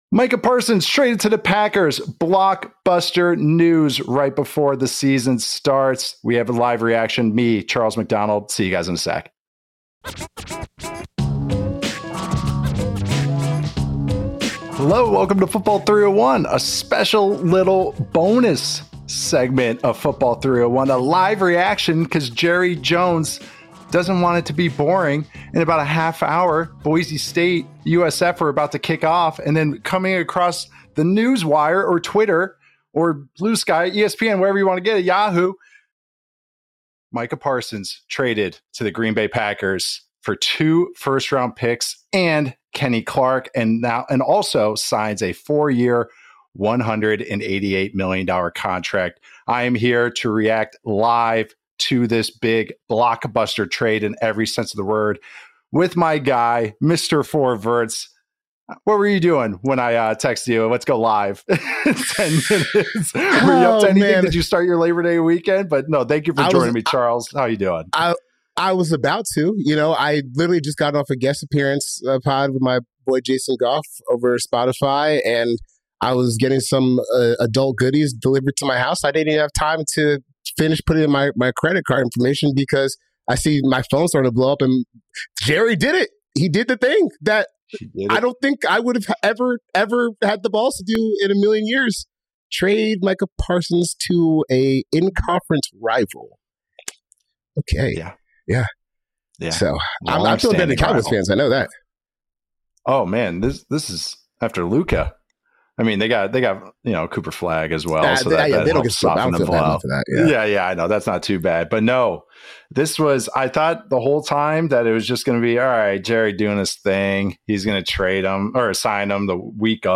Micah Parsons TRADED to the Packers! What does Dallas do next? LIVE instant reaction | Football 301